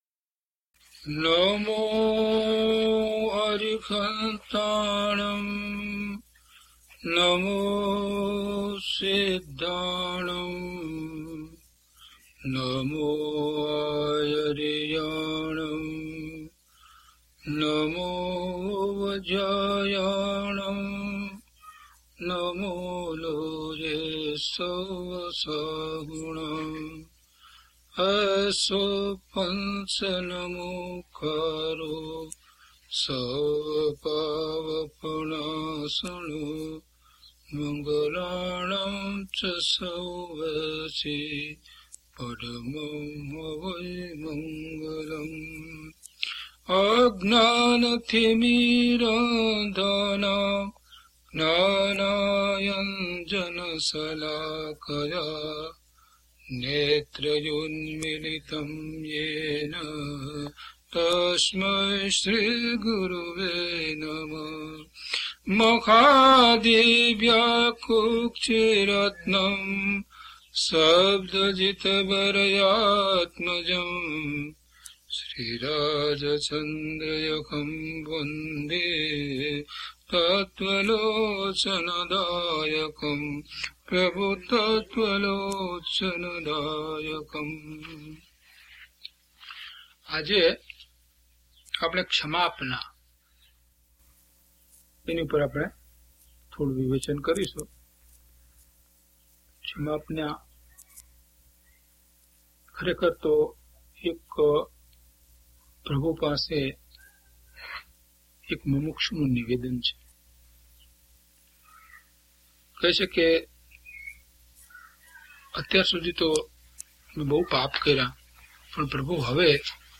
DHP037 Kshamapna Part 1 - Pravachan.mp3